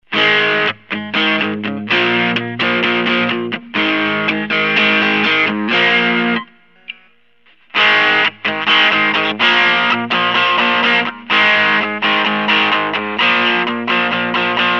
Mid Drive Sounds
Gain is set at the mid-point and the center and bridge pickups are used to demonstrate that it is still capable of some hard grunge.
All clips recorded through my AX84 tube amp set for a clean neutral tone using a stock 1970 Fender Stratocaster - miked with an SM-57. All distortion is being produced by the pedal.